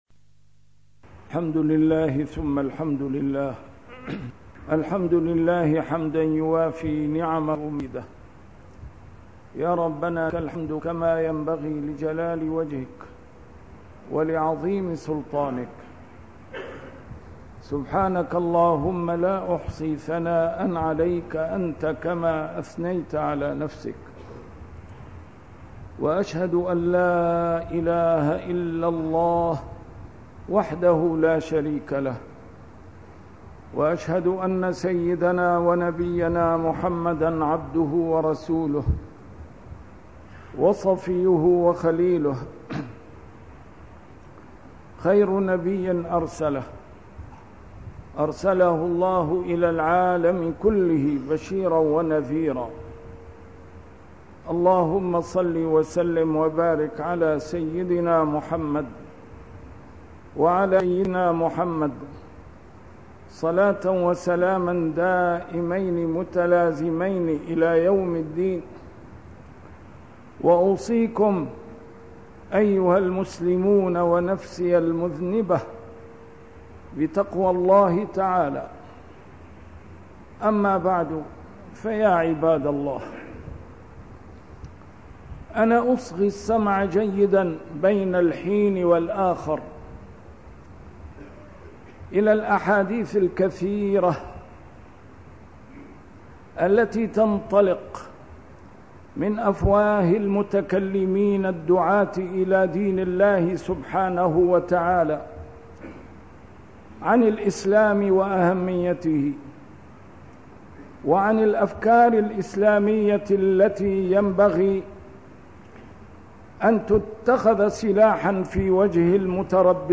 A MARTYR SCHOLAR: IMAM MUHAMMAD SAEED RAMADAN AL-BOUTI - الخطب - يكفيك العمل القليل